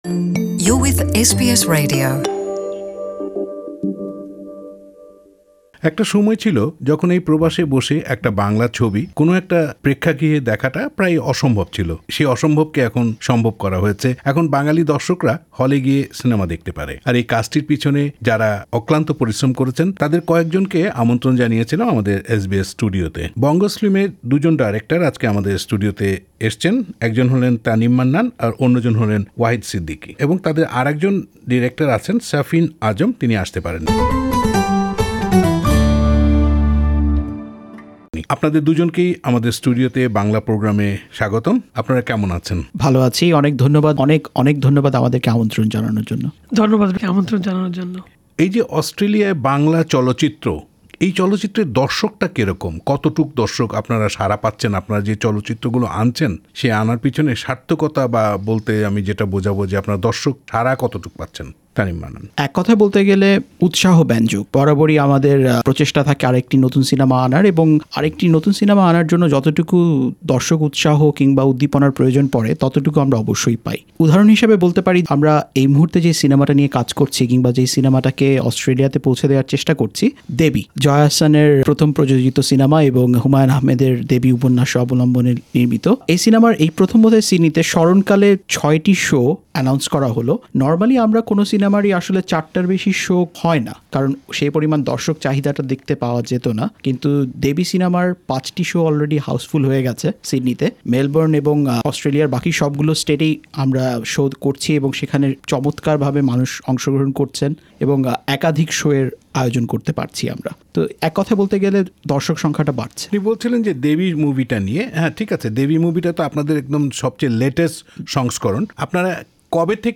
Source: SBS Bangla